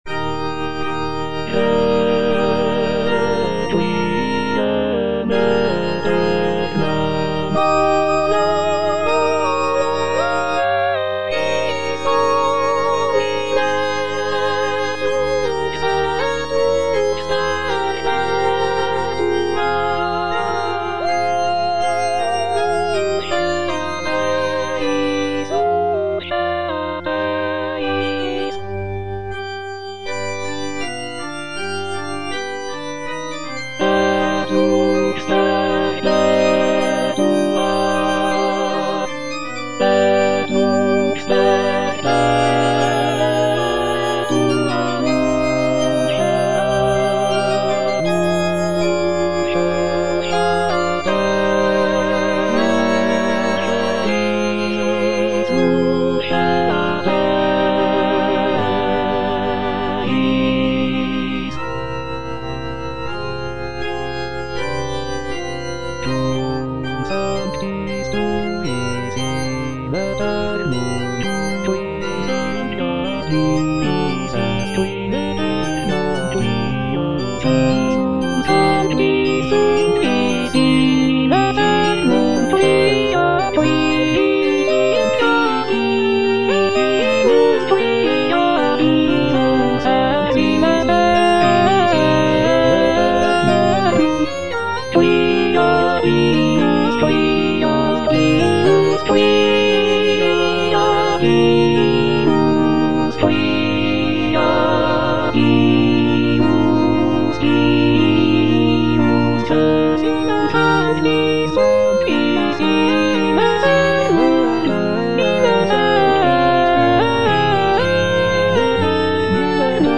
M. HAYDN - REQUIEM IN C (MISSA PRO DEFUNCTO ARCHIEPISCOPO SIGISMUNDO) MH155 Requiem aeternam - Alto (Emphasised voice and other voices) Ads stop: auto-stop
The work is characterized by its somber and mournful tone, reflecting the solemnity of a funeral mass.